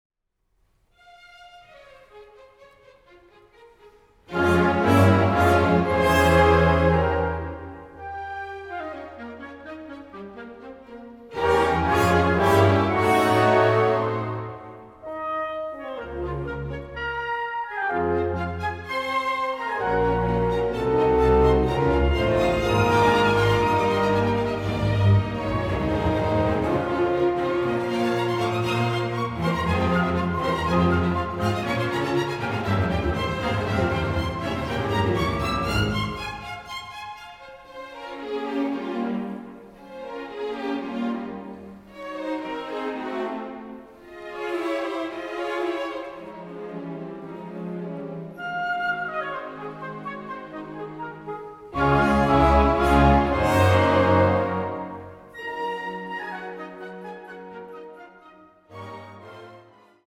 MDR-Sinfonieorchester
Dennis Russel Davies Dirigent
Der Live-Mitschnitt zeigt, auf welchem Niveau der Sinfoniker Bruckner in sein Oeuvre einsteigt und lässt in Details die Charakteristika der reifen Sinfonien aufblitzen.